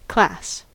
class: Wikimedia Commons US English Pronunciations
En-us-class.WAV